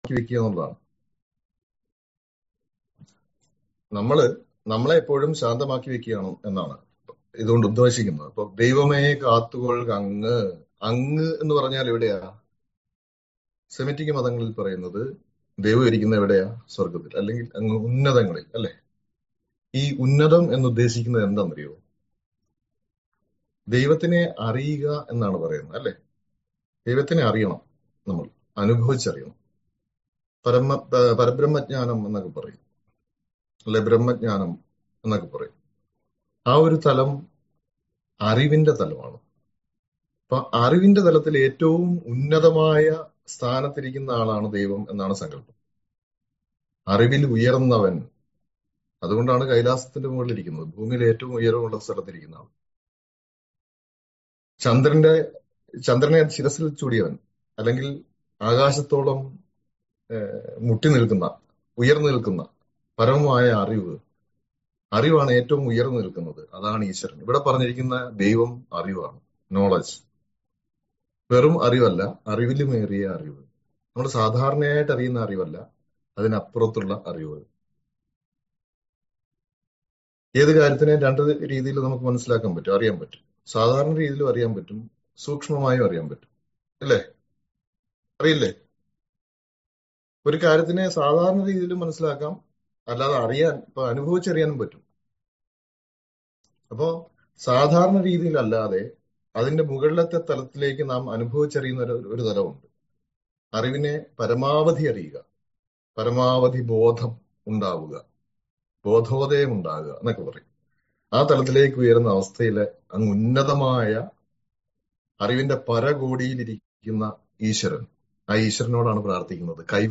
ദൈവദശകം - Audio Recitation